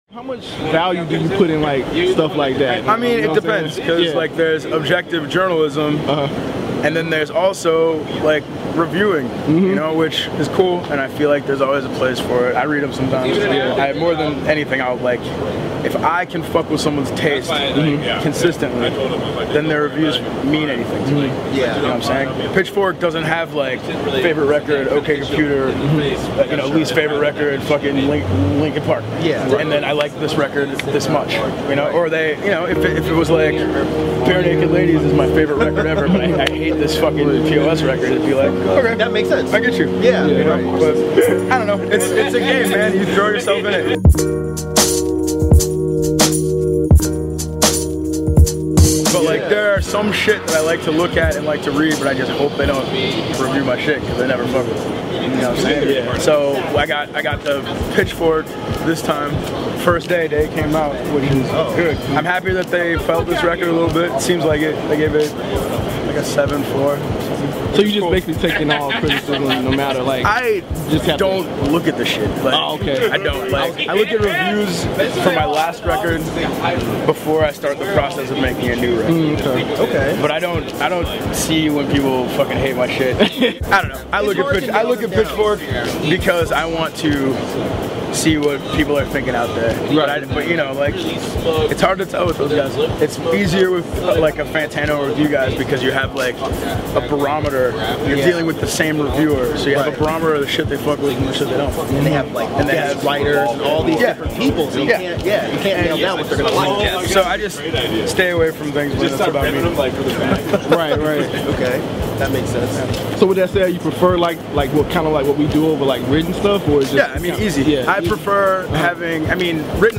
P.O.S of Doomtree Interview | DEHH